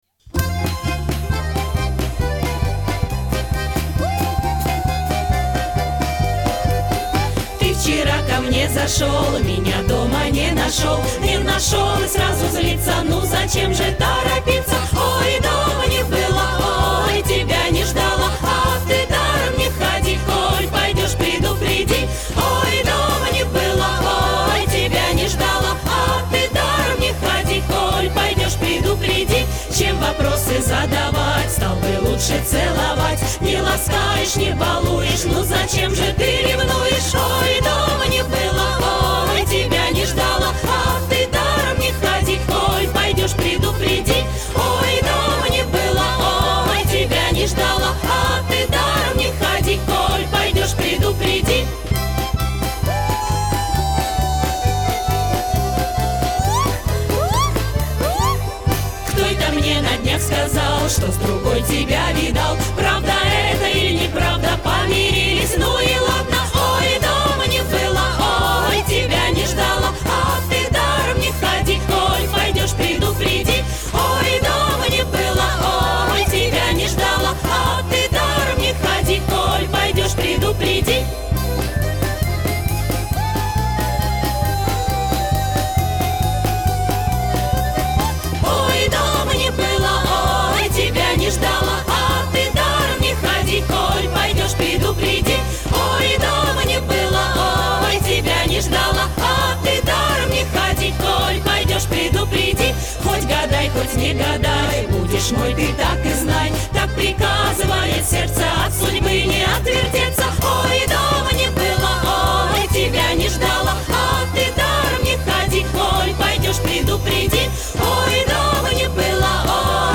10 Весёлая кадриль  русская народная песня.mp3